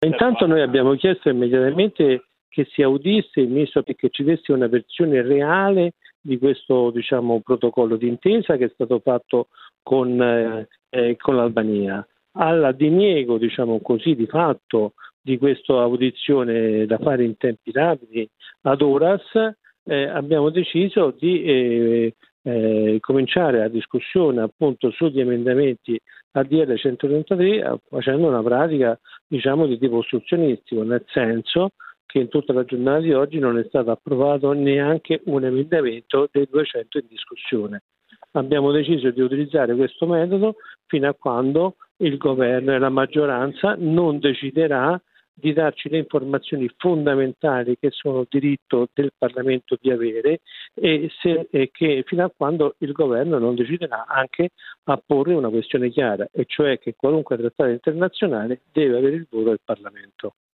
Filiberto Zaratti è un deputato di alleanza verdi e sinistra: